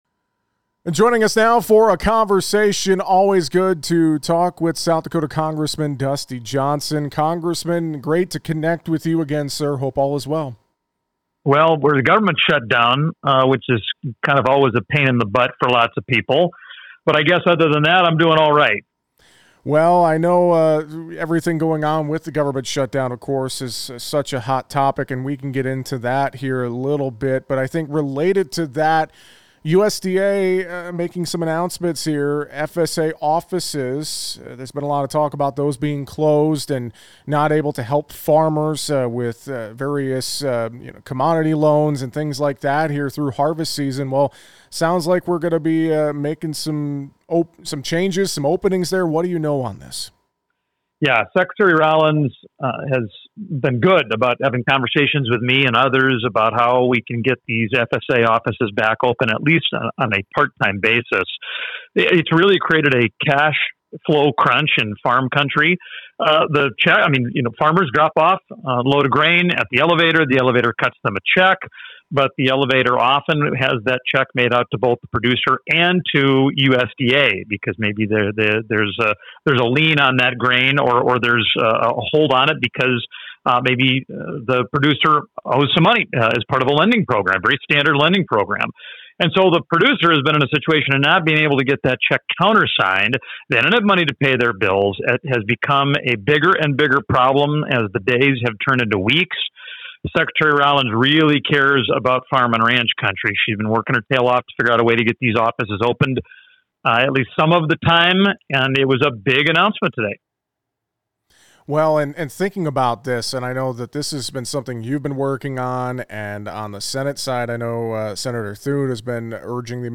Congressman Dusty Johnson (R-SD) said in an interview with us late Tuesday that he and others had been working with Secretary Rollins to get FSA offices reopened for critical services.
Hear the full conversation with Congressman Dusty Johnson below: